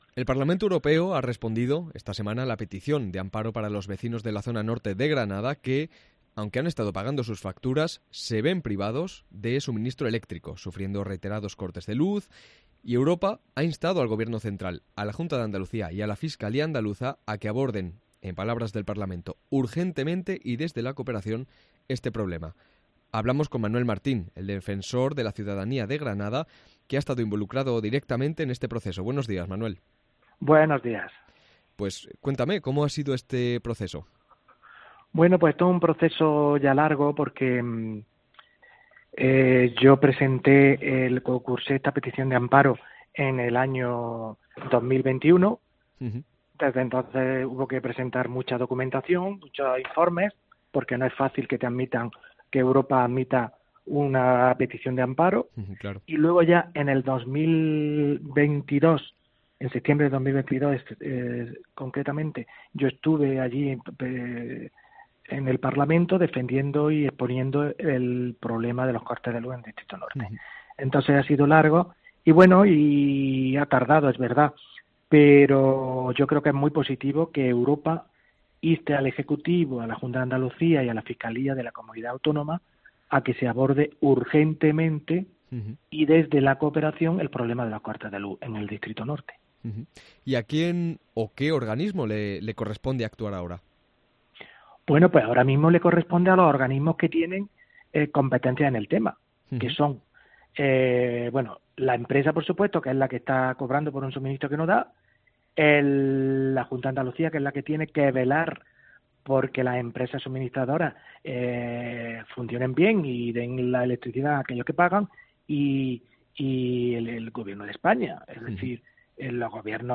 Entrevista a Manuel Martín, Defensor de la Ciudadanía de Granada